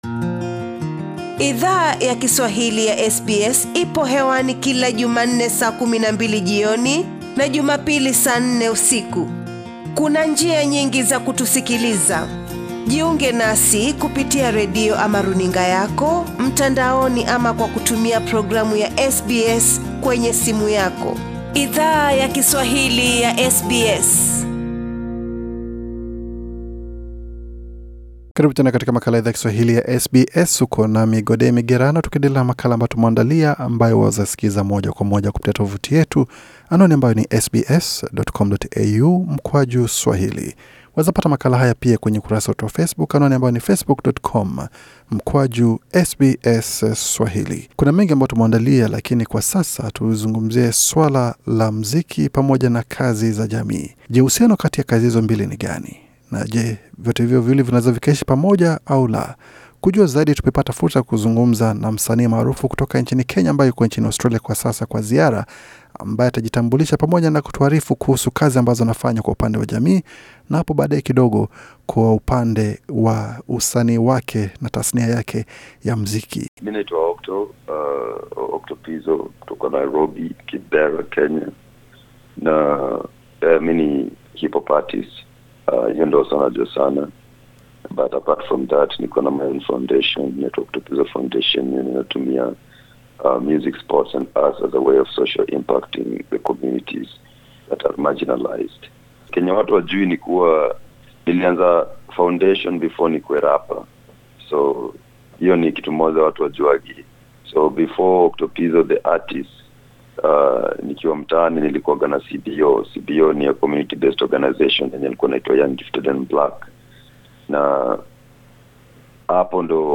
SBS Swahili ilizungumza na Octopizzo, alipokuwa katika ziara kusini Australia, ambako alishiriki katika tamasha ya kila mwaka maarufu kwa jina la Sanaa Festival mjini Adelaide, pamoja nakushiriki katika jopo aliko zungumza kuhusu kazi ya shirika lake la misaada la Octopizzo Foundation.